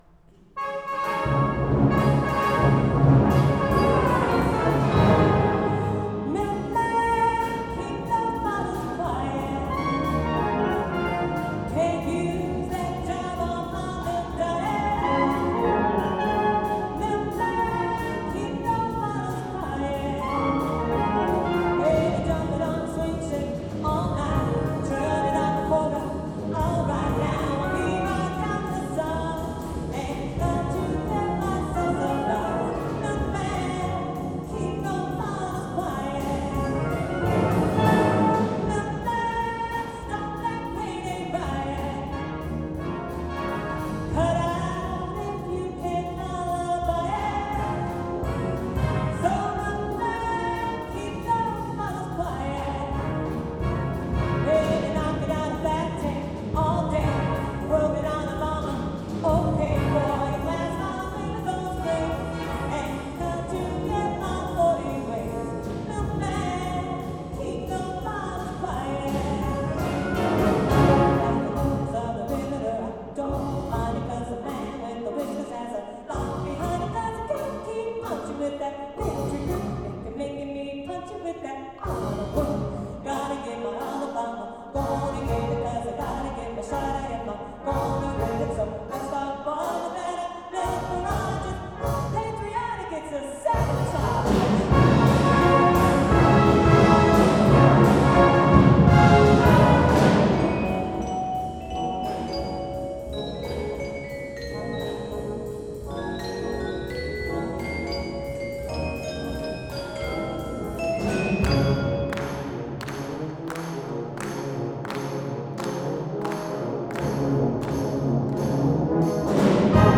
Performance #274 – Friday, October 6, 2023 – 7:30 PM
St. Andrews Lutheran Church, Mahtomedi, MN